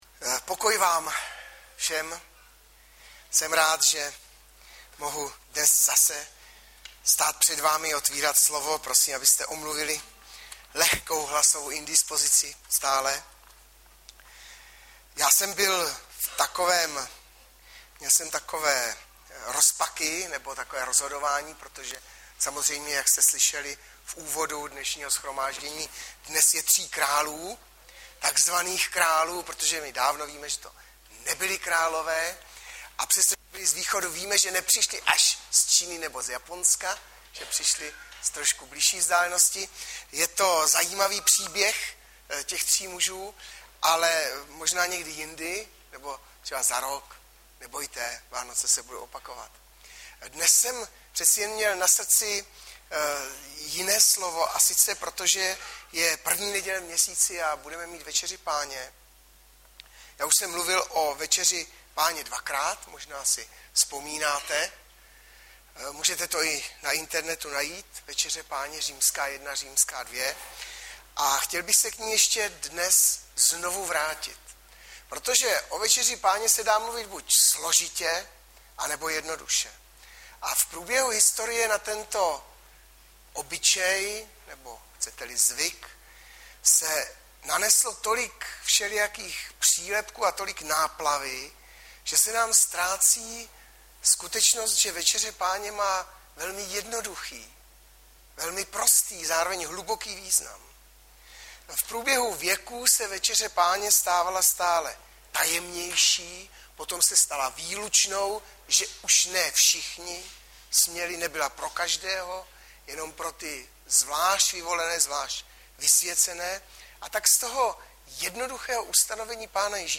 Webové stránky Sboru Bratrské jednoty v Litoměřicích.
- SMLOUVA - 1Kor 11,23-26 Audiozáznam kázání si můžete také uložit do PC na tomto odkazu.